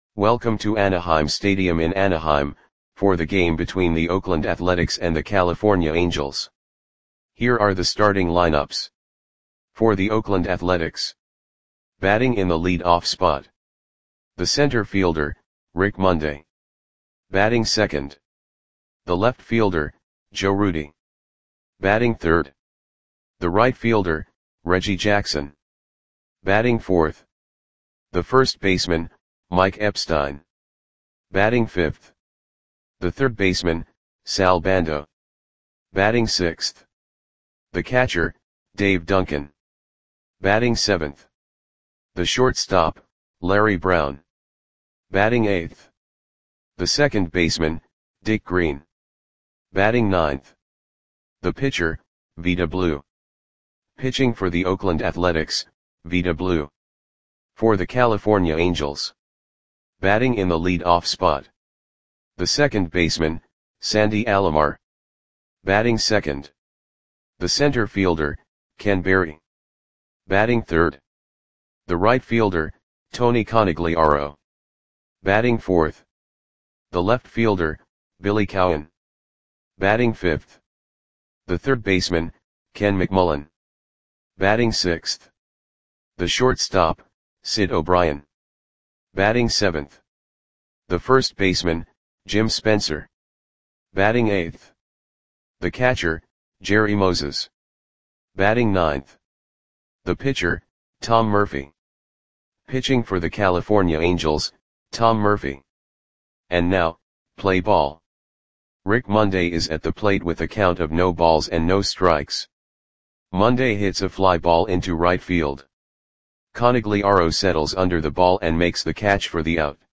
Audio Play-by-Play for California Angels on July 4, 1971
Click the button below to listen to the audio play-by-play.